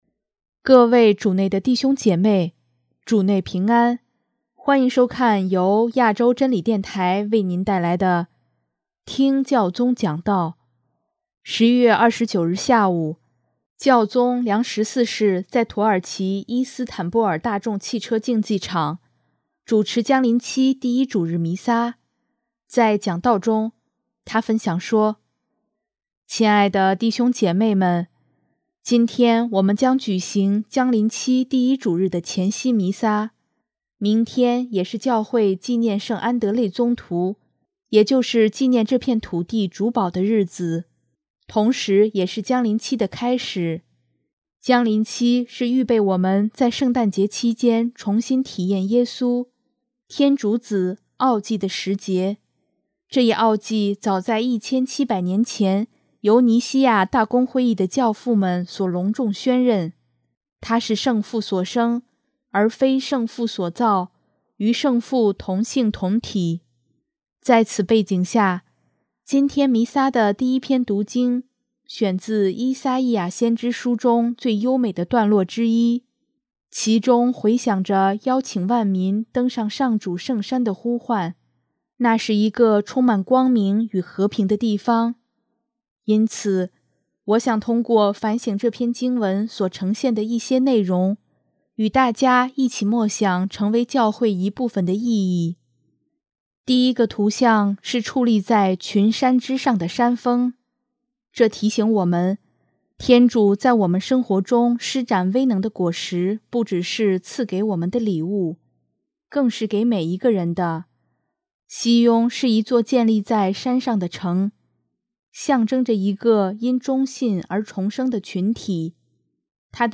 11月29日下午，教宗良十四世在土耳其伊斯坦布尔大众汽车竞技场（Volkswagen Arena）主持将临期第一主日弥撒，在讲道中，他分享说：